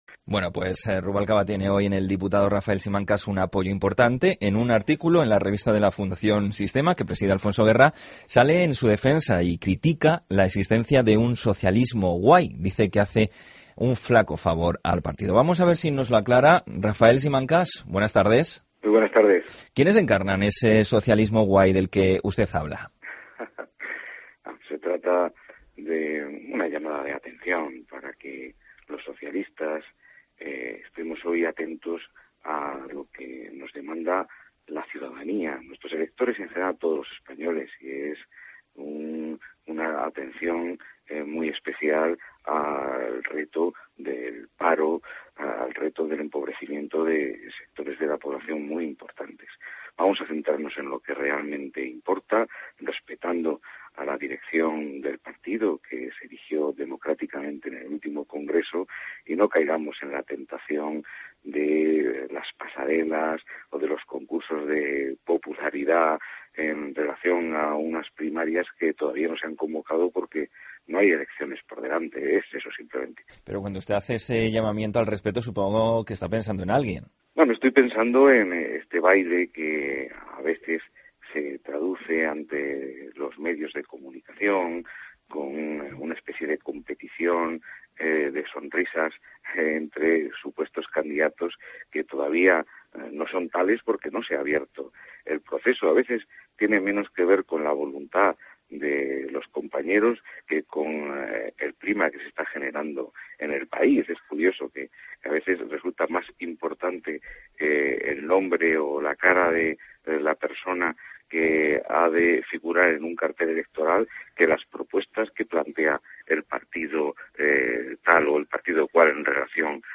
Entrevista a Rafael Simancas en Mediodia COPE